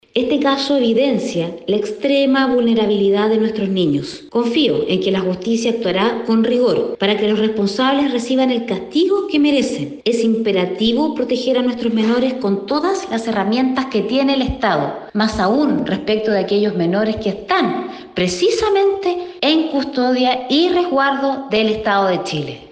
Por otro lado, la parlamentaria de Renovación Nacional, Camila Flores, destacó que este caso refleja la extrema vulnerabilidad de los niños del país.